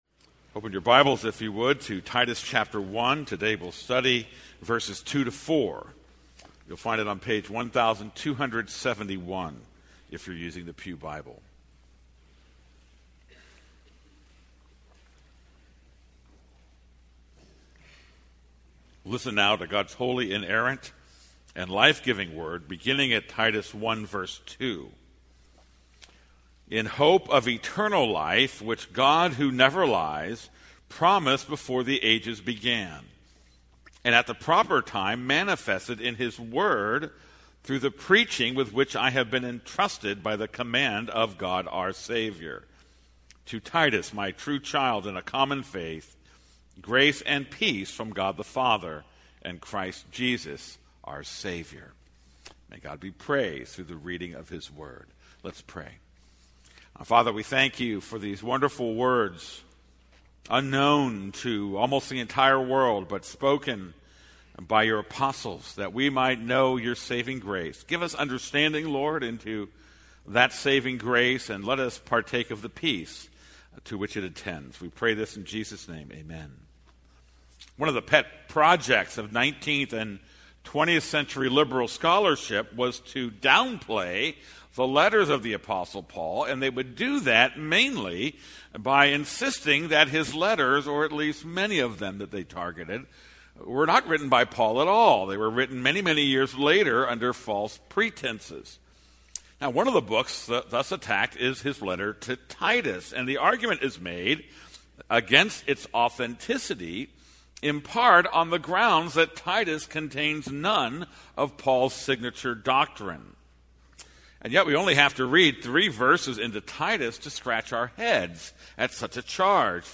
This is a sermon on Titus 1:2-4.